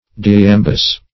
Search Result for " diiambus" : The Collaborative International Dictionary of English v.0.48: Diiambus \Di`i*am"bus\, n. [NL., fr. Gr.